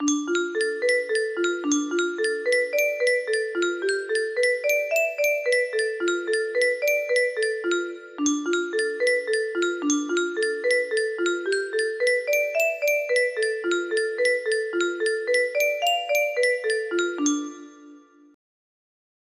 Hårgalåten - Generic music box melody